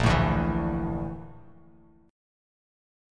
BbM#11 chord